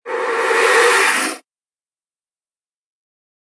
Descarga de Sonidos mp3 Gratis: hechizo 4.